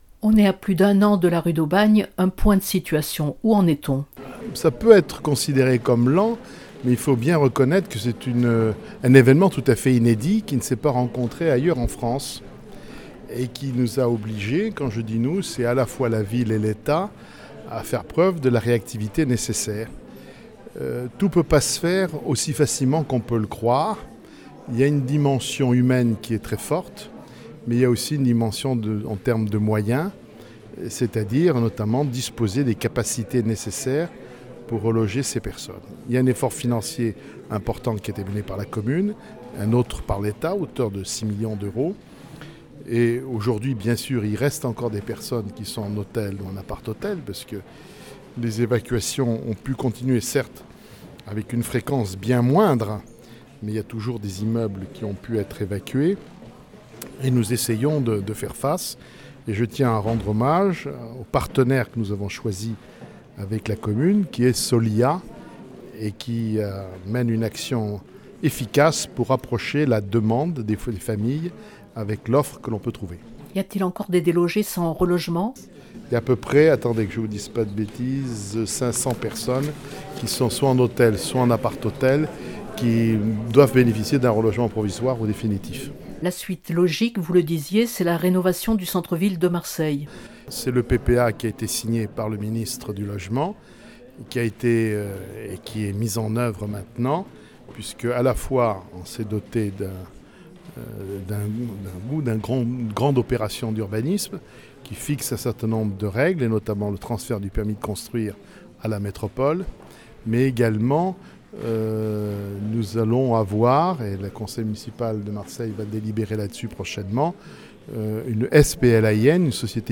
Urbanisme, logement, environnement, social, économie : le préfet de la région Pierre Dartout a abordé plus d’un sujet lors du traditionnel exercice des vœux à la presse, dressant là le bilan d’une année charnière.